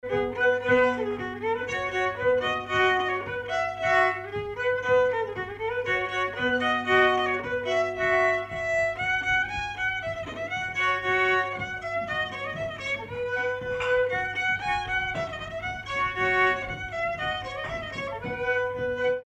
Polka
Villard-sur-Doron
danse : polka
circonstance : bal, dancerie
Pièce musicale inédite